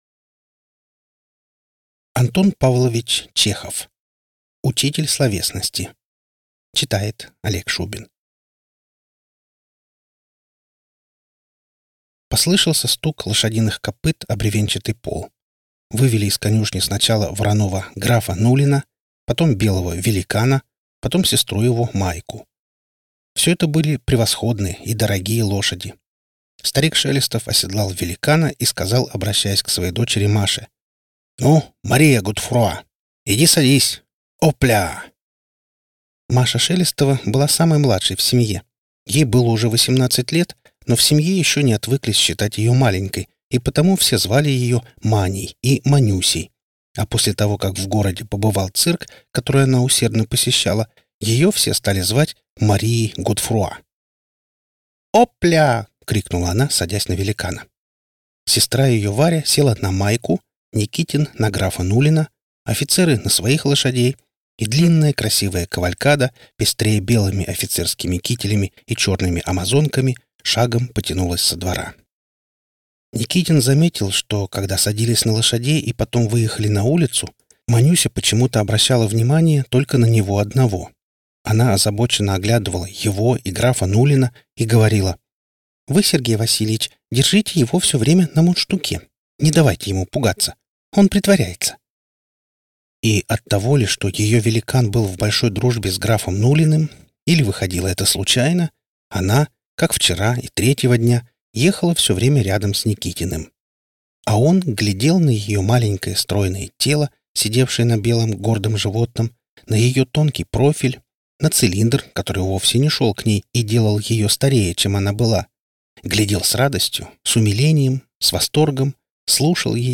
Аудиокнига Учитель словесности - купить, скачать и слушать онлайн | КнигоПоиск